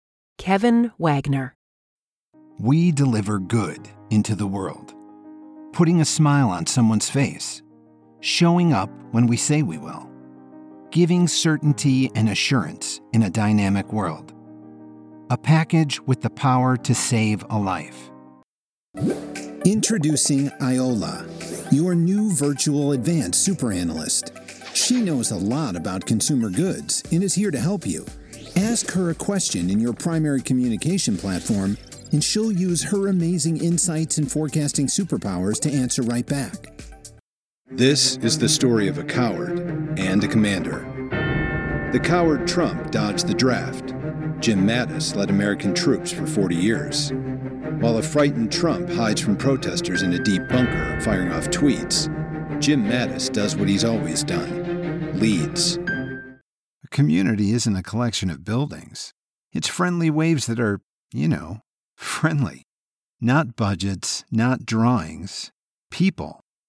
Demos
KWCommericalDemo.mp3